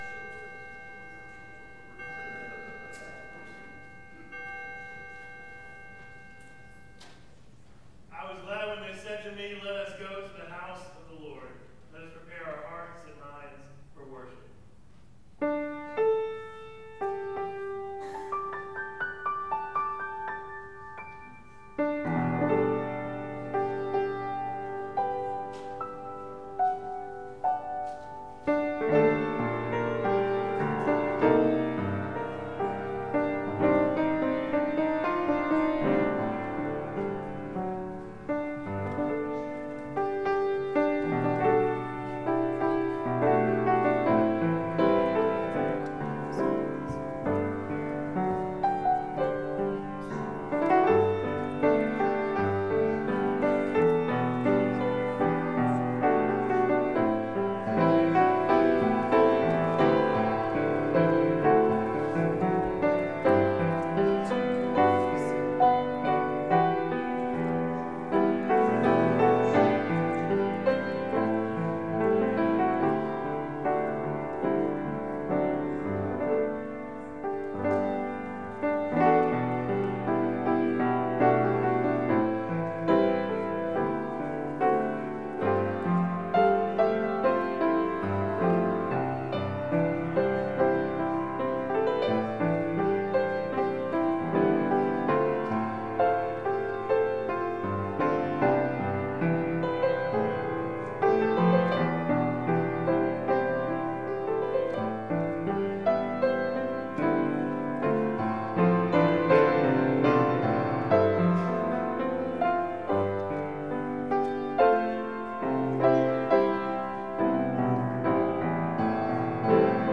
Ecclesiastes 3:9-15 Service Type: Morning Bible Text